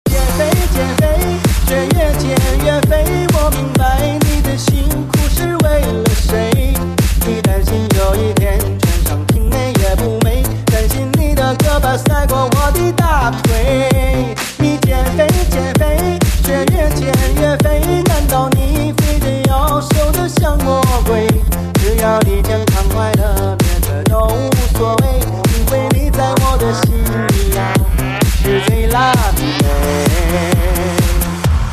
DJ铃声, M4R铃声, MP3铃声 91 首发日期：2018-05-15 09:15 星期二